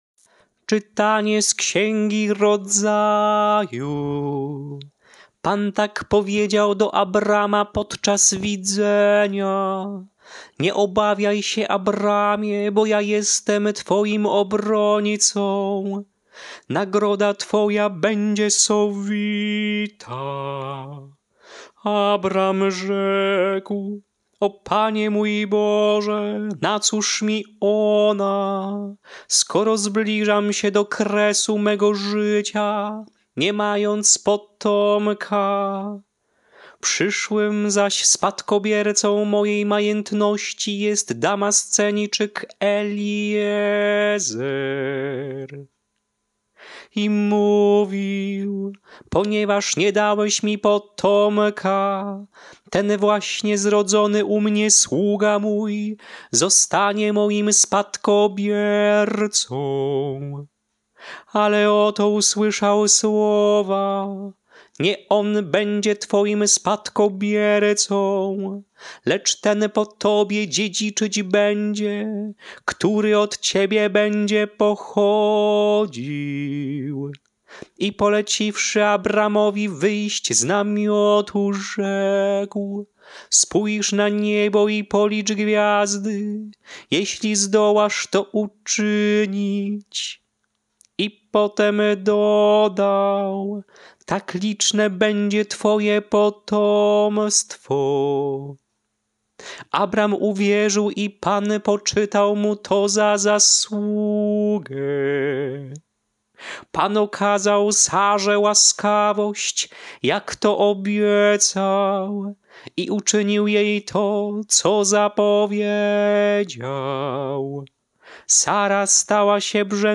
Śpiewane lekcje mszalne – Święto św. Rodziny
Melodie lekcji mszalnych przed Ewangelią na Święto św. Rodziny:
Sw.-Rodziny-ton-proroctwa-1.mp3